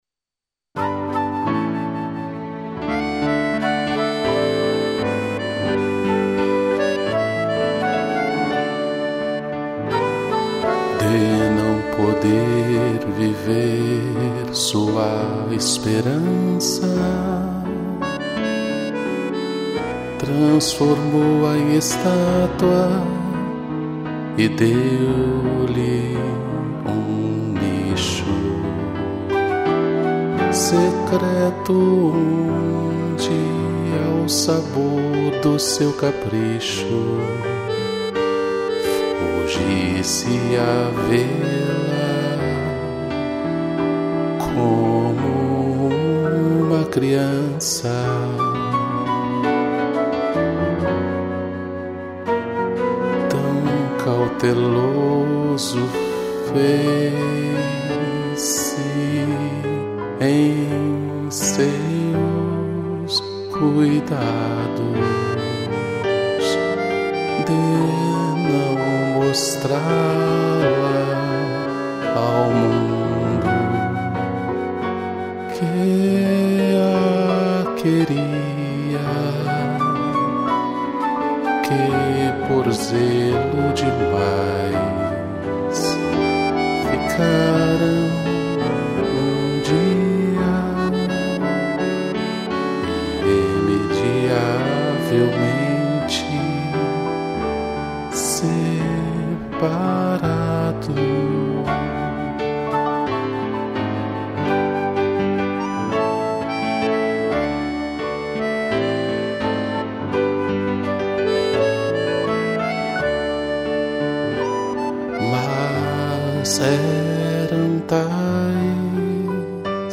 piano, flauta, acordeão e cello